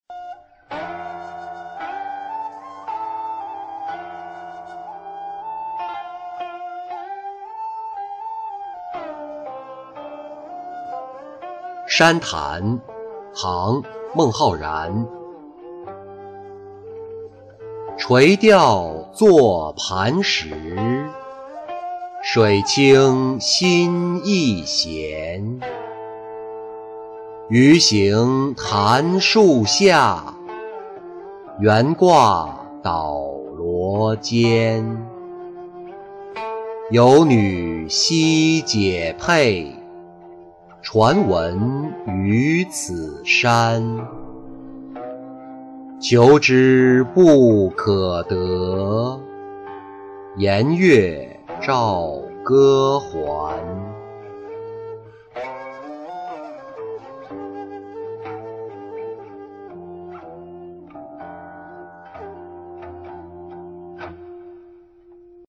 万山潭作-音频朗读